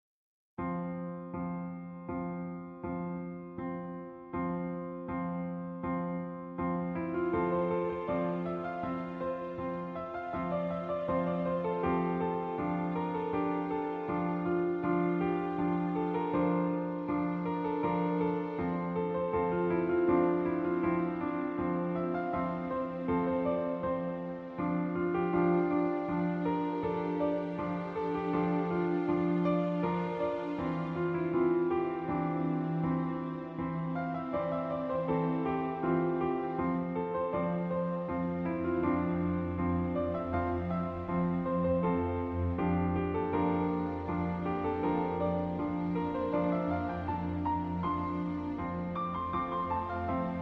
Original soundtrack